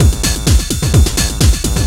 DS 128-BPM B1.wav